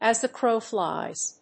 アクセントas the crów flíes